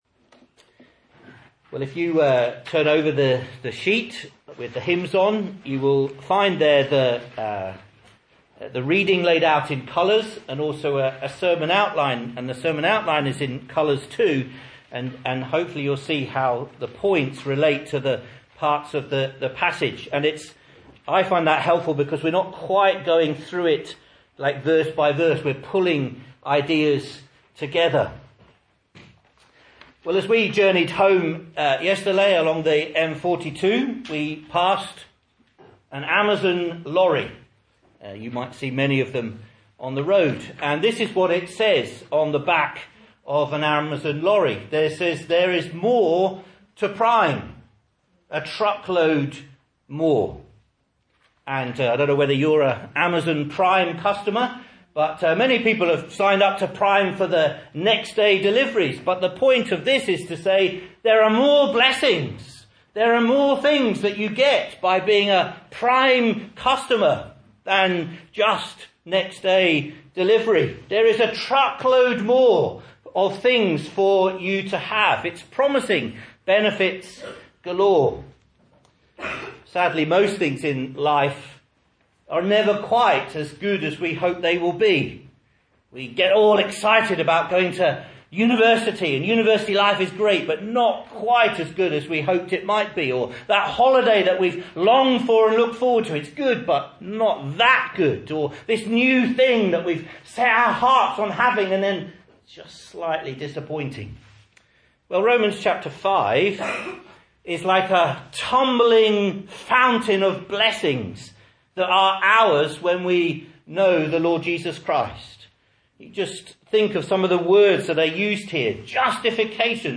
Message Scripture: Romans 5:1-11 | Listen